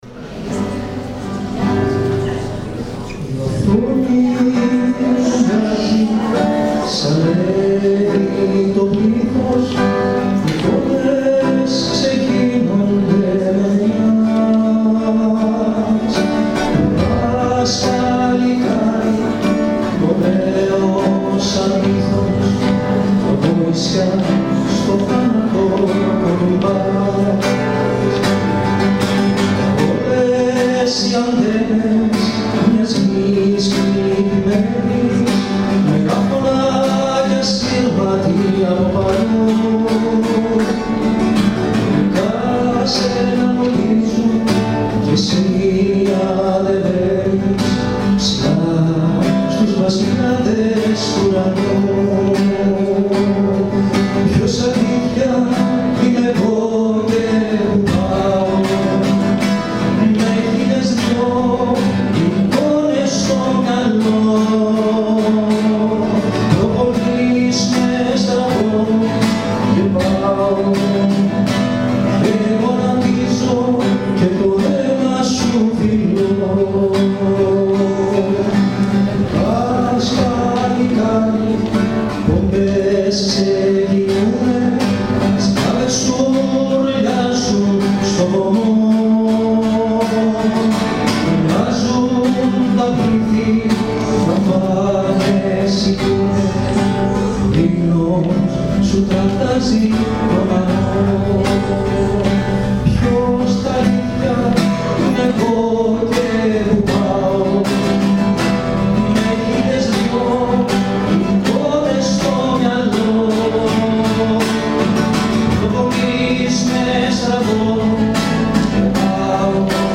Μια καταπληκτική γιορτή μνήμης για τη 17 Νοέμβρη διοργάνωσαν οι μαθητές του σχολείου μας μαζί με τους καθηγητές τους. Πάρτε μια γεύση ακούγοντας την χορωδία.